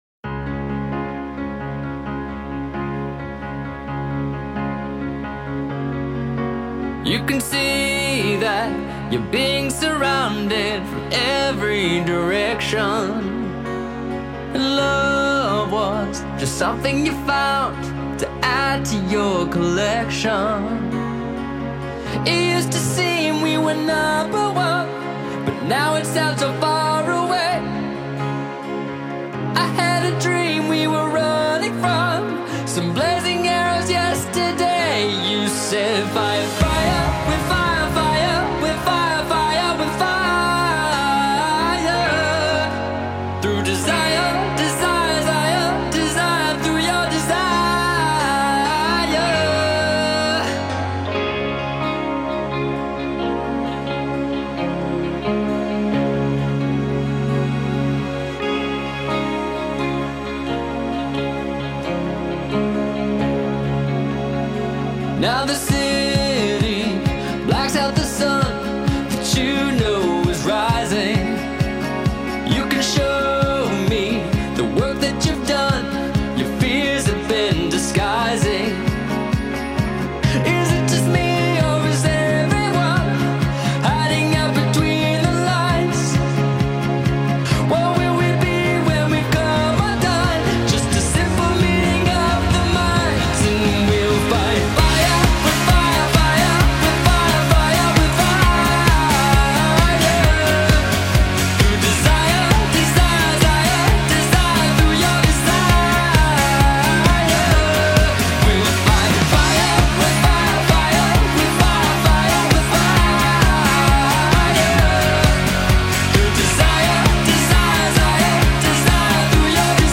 Genre: Rap & Hip-Hop.